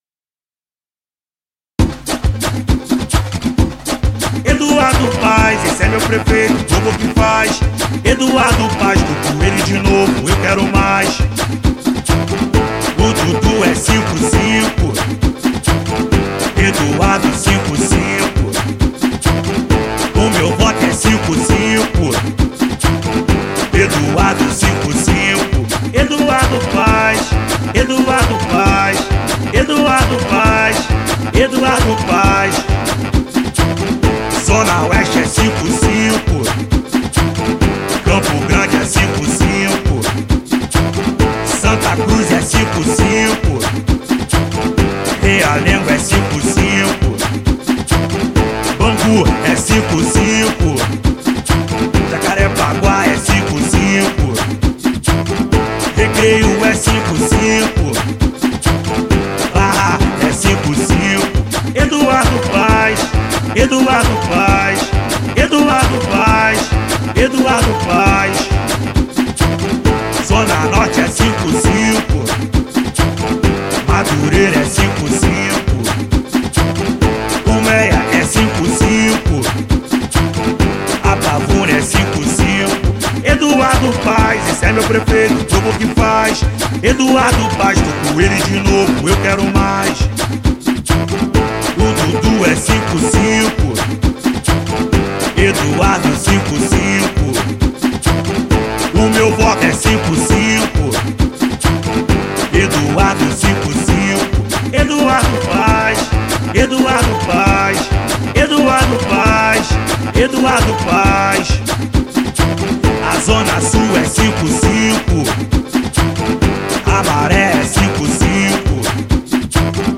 jingle versão funk II